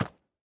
minecraft / sounds / mob / goat / step2.ogg